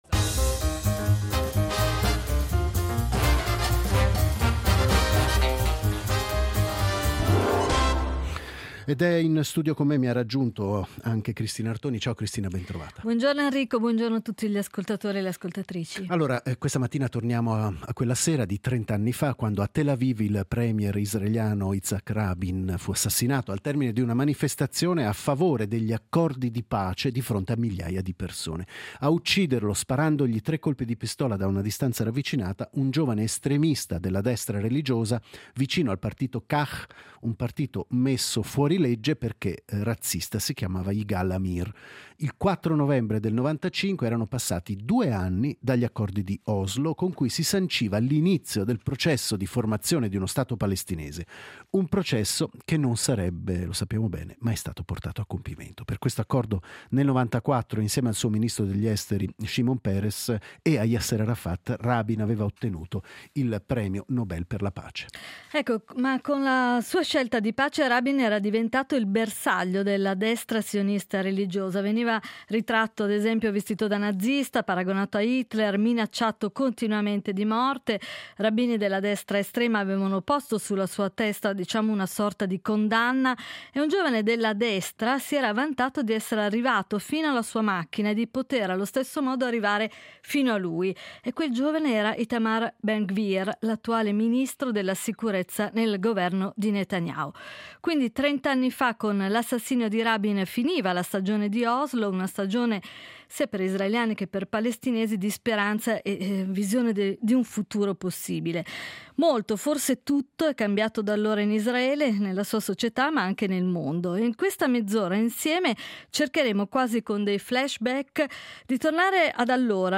A confronto due ospiti